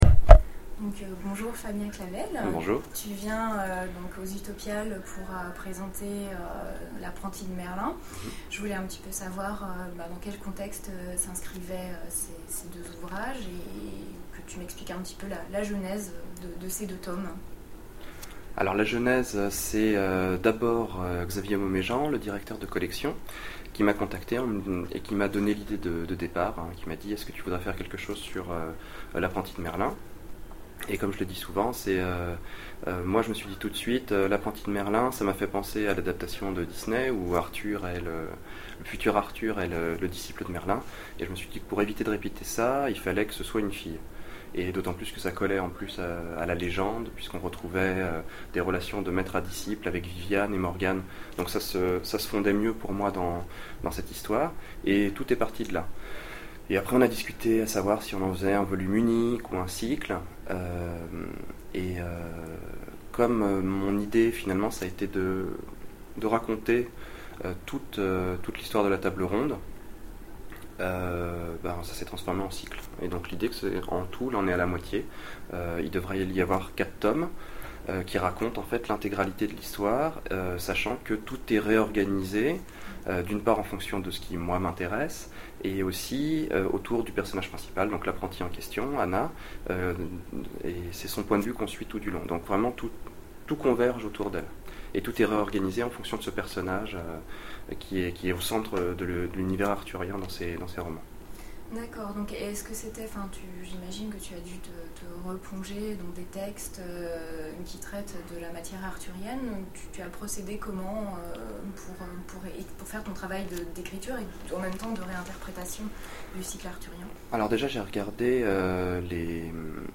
Utopiales 2011 : Interview